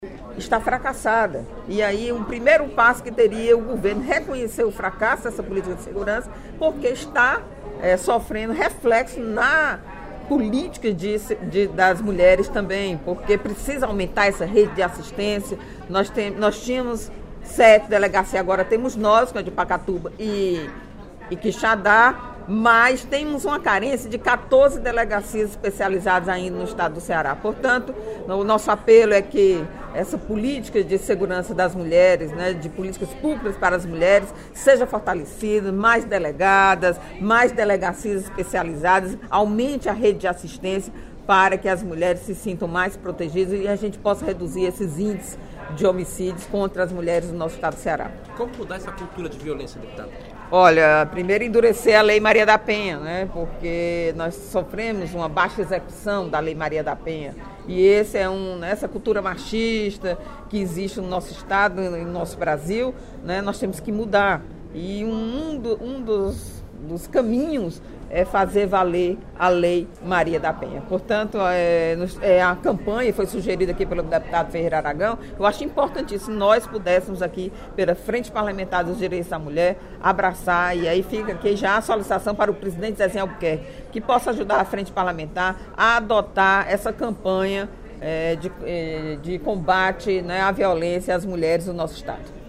A deputada Eliane Novais (PSB) destacou, durante o primeiro expediente da sessão plenária desta terça-feira (06/05), matéria veiculada no jornal Diário do Nordeste de segunda-feira (05) que aborda o número de homicídios contra mulheres no Estado.